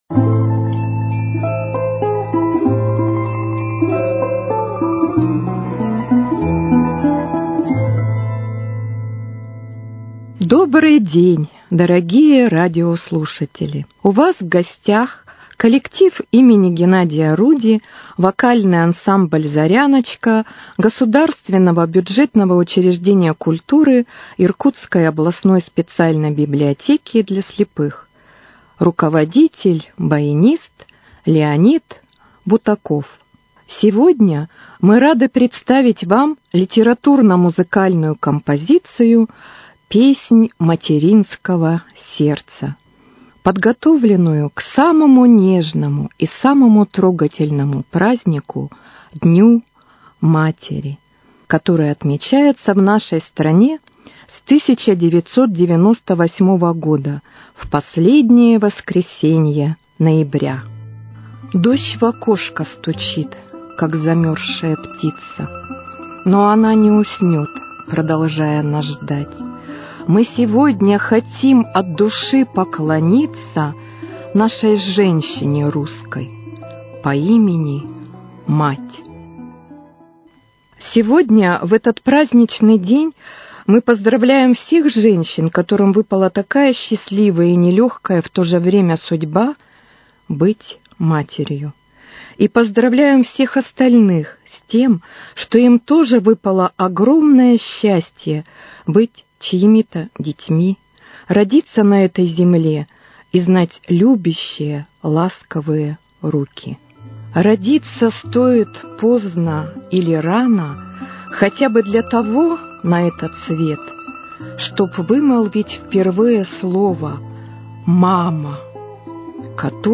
вокальным ансамблем
баянист